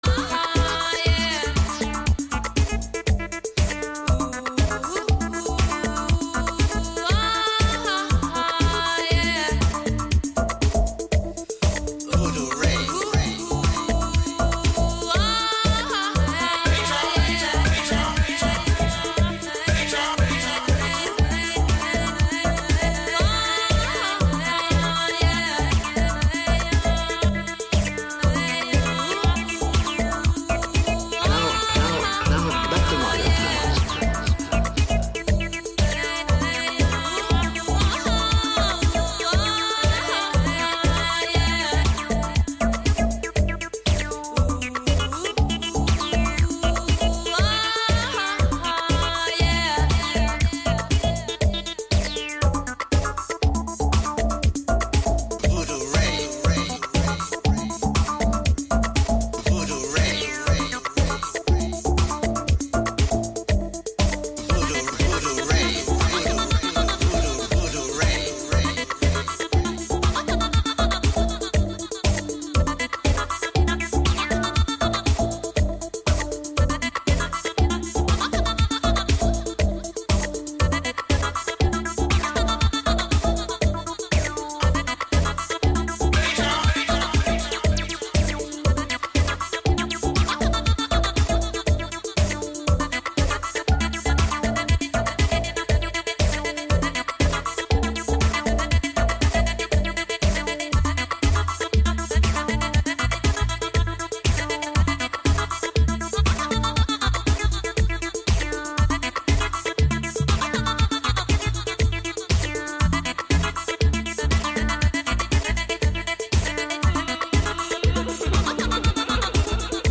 dance music